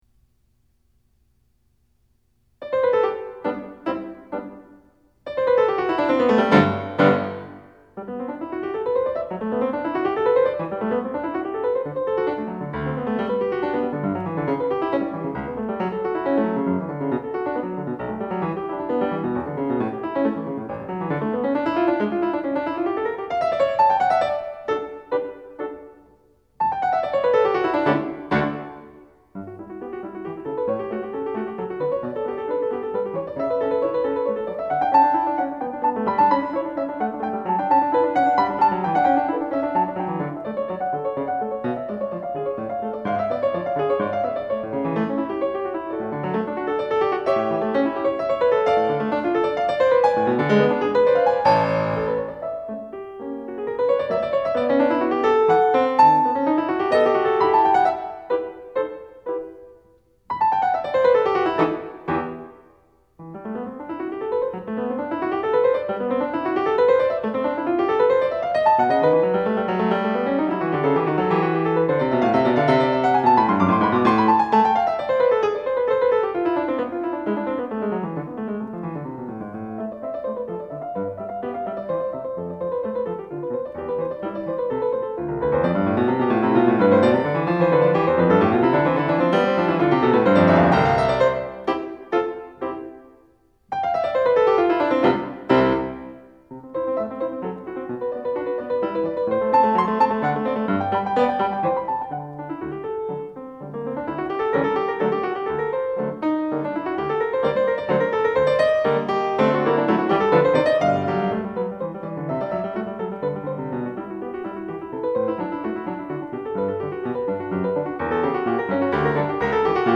Selected live recordings